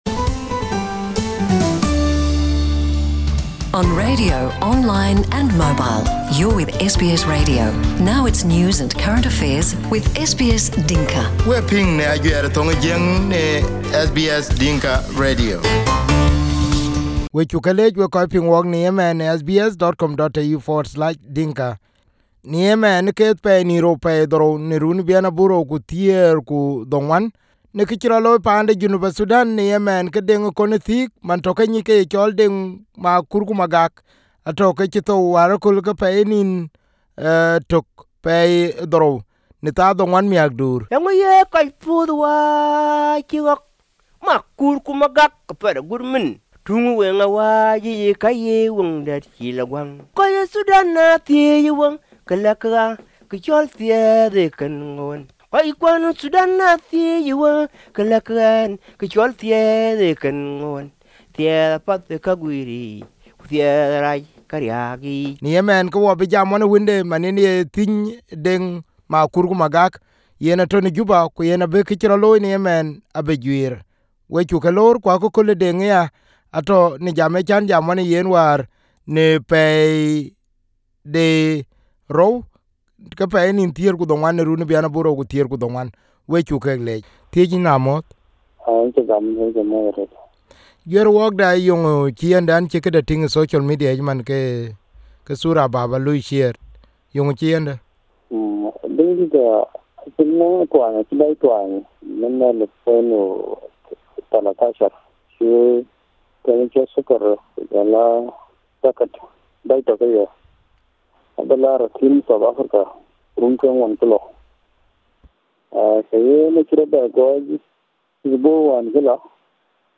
the son has said in an interview on SBS Dinka.